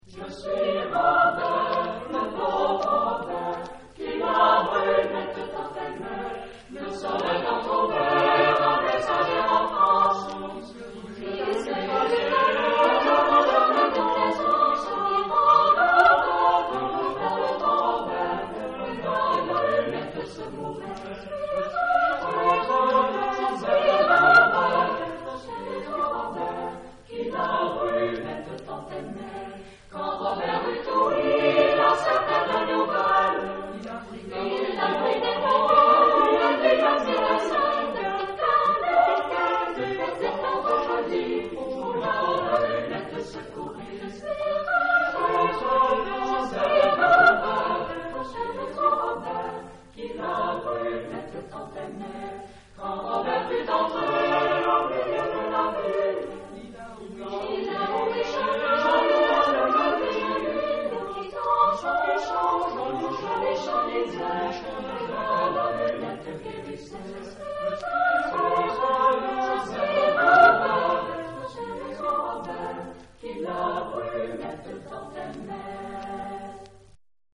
Género/Estilo/Forma: Renacimiento ; Profano
Tipo de formación coral: SATB  (4 voces Coro mixto )